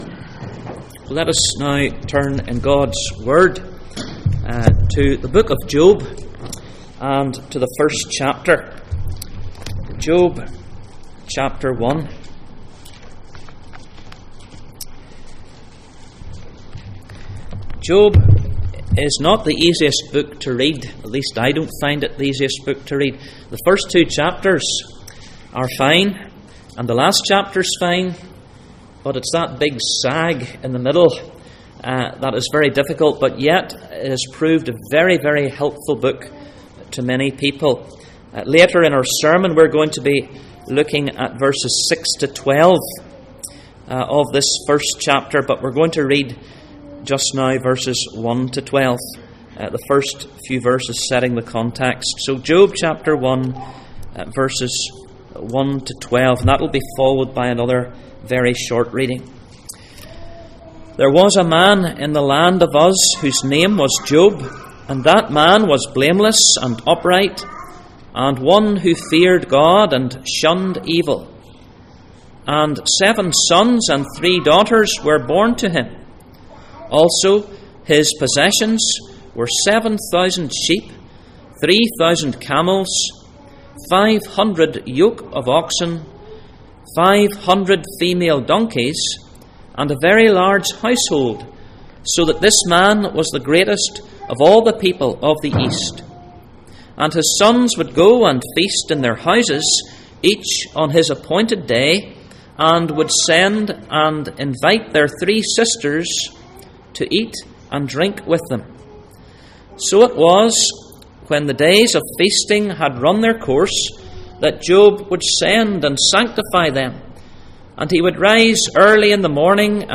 Job 19:25 Service Type: Sunday Morning %todo_render% « Will we forget Jesus?